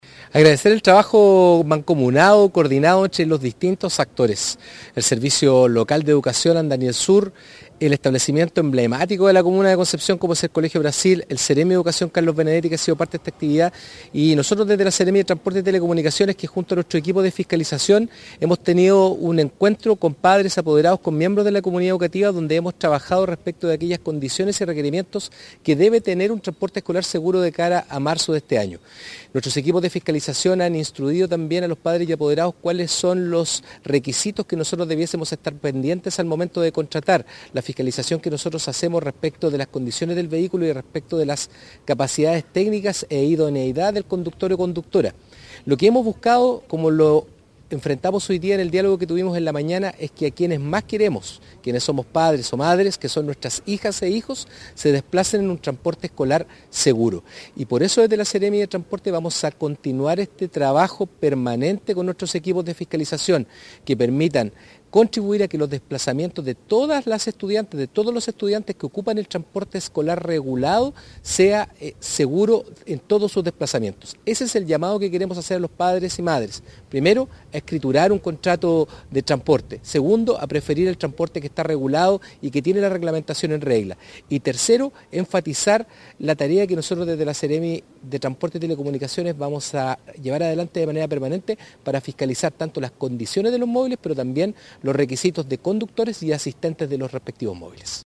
El seremi de Transportes entregó más detalles al respecto.